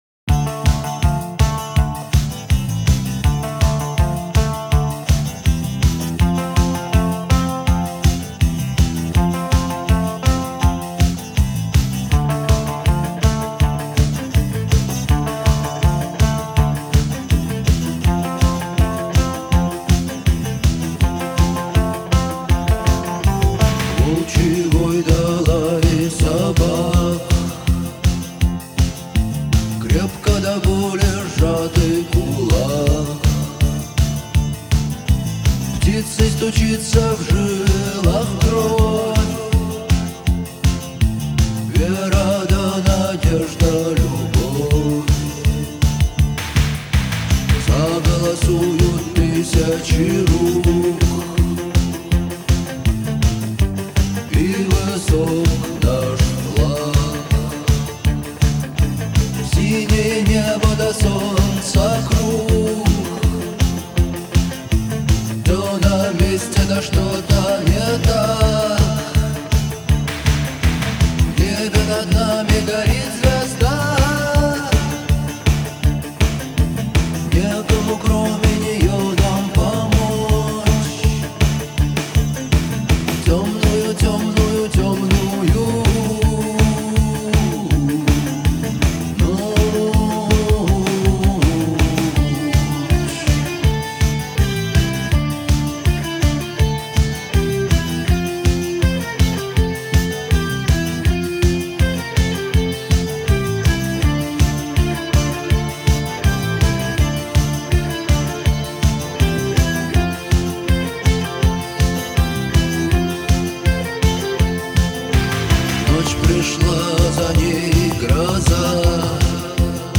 характерными гитарными рифами
проникновенным вокалом